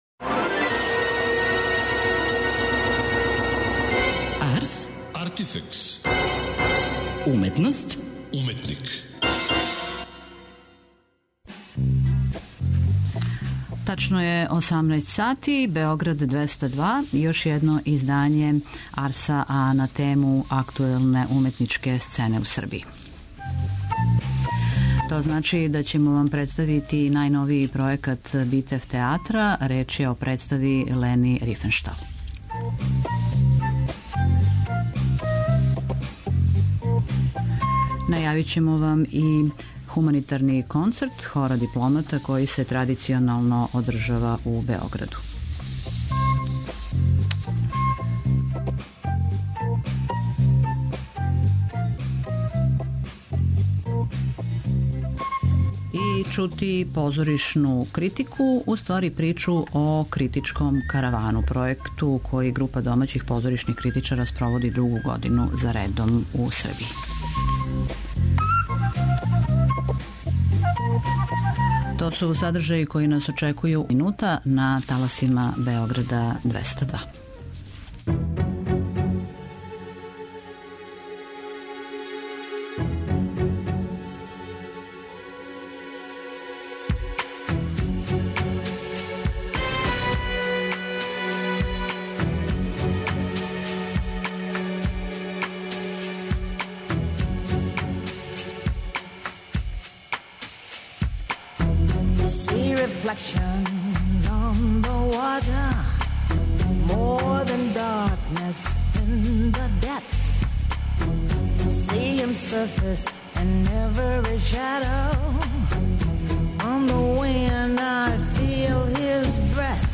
преузми : 26.26 MB Ars, Artifex Autor: Београд 202 Ars, artifex најављује, прати, коментарише ars/уметност и artifex/уметника. Брзо, кратко, критички - да будете у току.